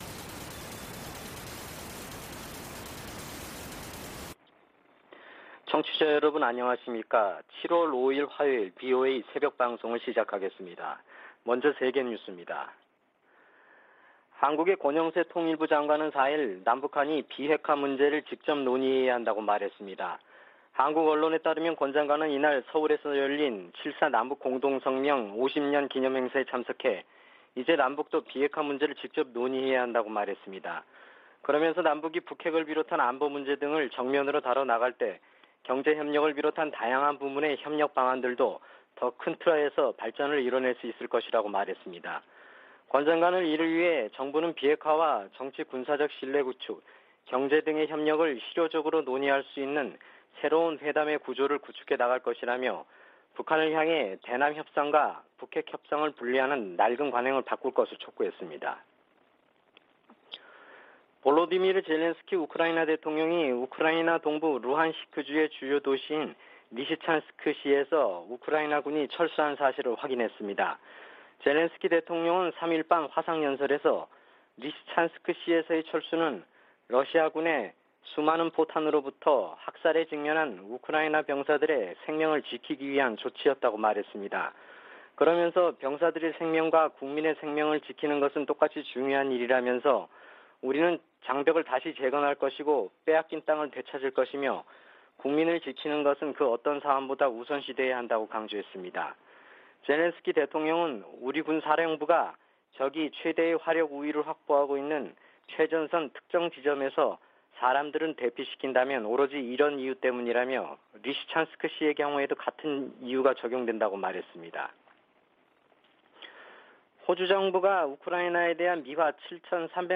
VOA 한국어 '출발 뉴스 쇼', 2022년 7월 5일 방송입니다. 미국은 북한의 핵·미사일 도발에 대응하고 한반도 비핵화를 위해 동맹과 협력할 것이라고 미군 당국이 밝혔습니다. 위협을 가하는 북한에 일방적으로 대화와 협력을 요청해서는 변화시킬 수 없다고 전 국무부 동아시아태평양 담당 차관보가 진단했습니다. 미국 전문가들은 중국에 대한 한국의 전략적 모호성은 이익보다 대가가 클 것이라고 말했습니다.